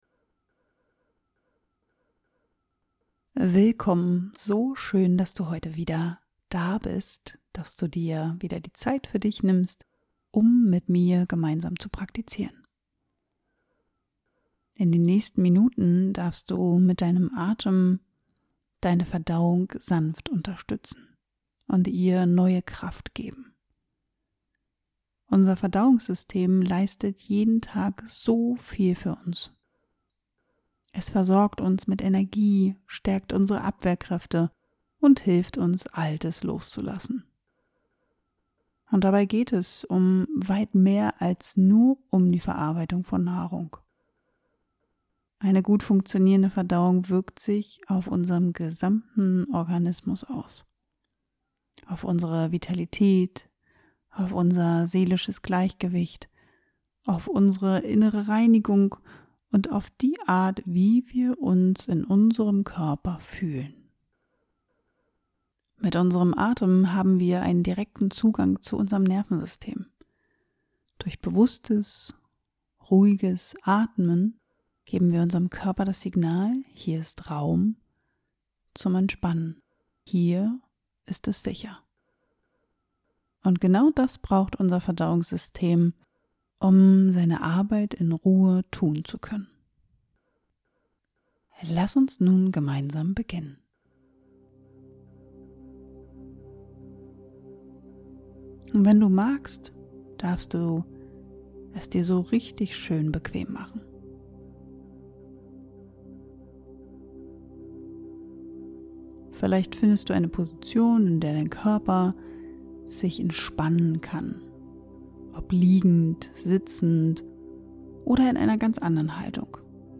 Gönn dir eine Auszeit und bring deine Verdauung wieder in Balance. In dieser 15-minütigen geführten Atemmeditation spürst du, wie du mit deinem Atem dein Nervensystem beruhigen und deine Verdauung auf natürliche Weise unterstützen kannst.